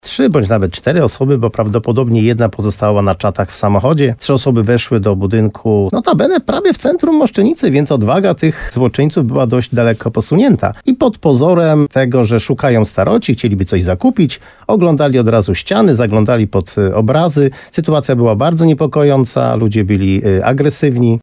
– mówił wójt Jerzy Wałęga w programie Słowo za Słowo na antenie RDN Nowy Sącz.